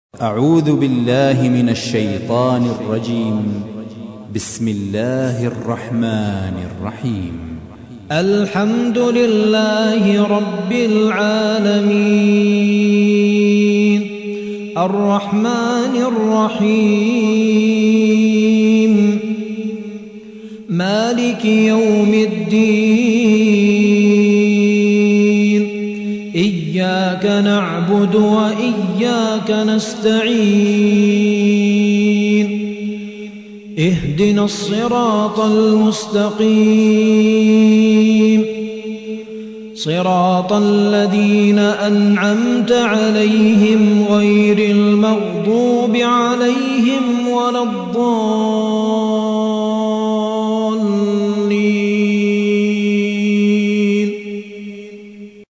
1. سورة الفاتحة / القارئ